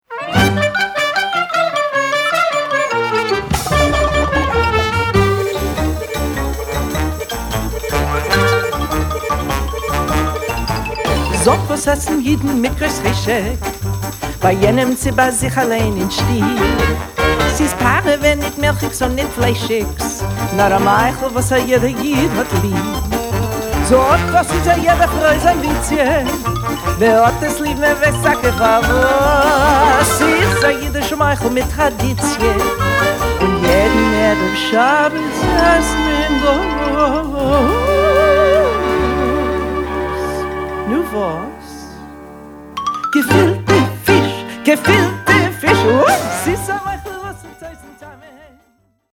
Genres: Yiddish, World.
The theater song